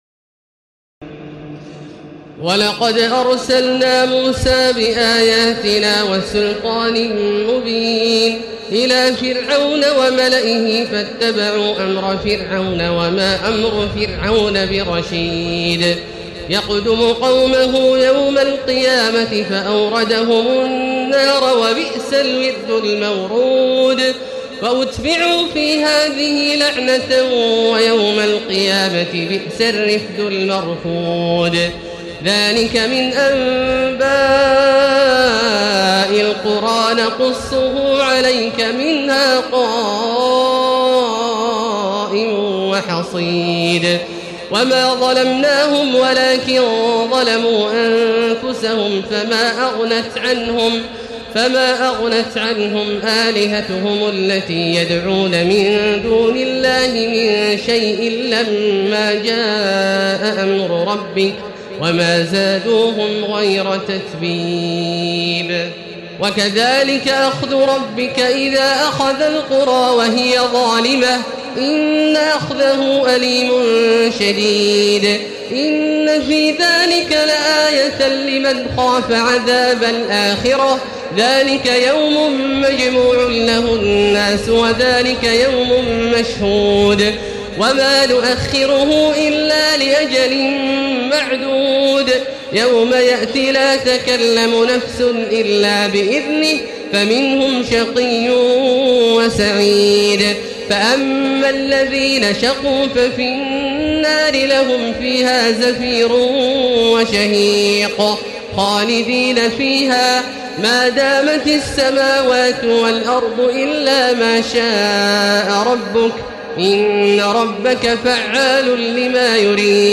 تراويح الليلة الحادية عشر رمضان 1436هـ من سورتي هود (96-123) و يوسف (1-57) Taraweeh 11 st night Ramadan 1436H from Surah Hud and Yusuf > تراويح الحرم المكي عام 1436 🕋 > التراويح - تلاوات الحرمين